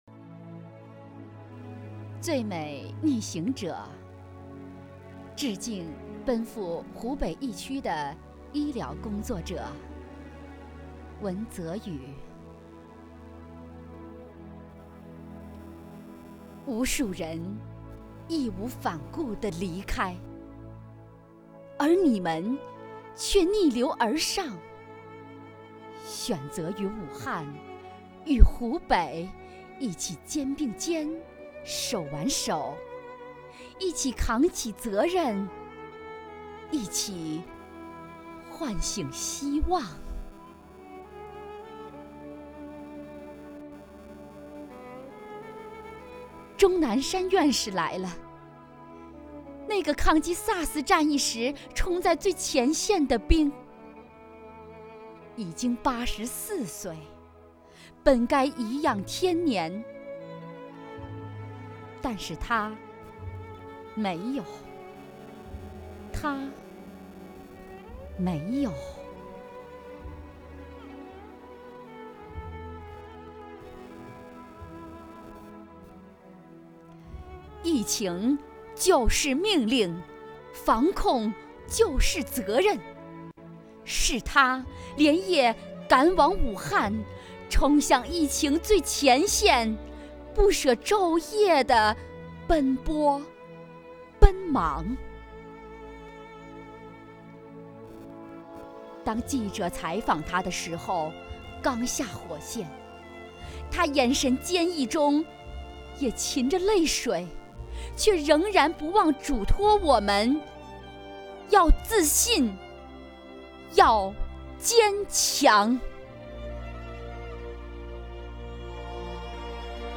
为声援战斗在一线的工作人员，鼓舞全区人民抗击疫情的信心和决心，丰南文化馆、丰南诗歌与朗诵协会继续组织诗歌与诵读工作者、爱好者共同创作录制诵读作品。
朗诵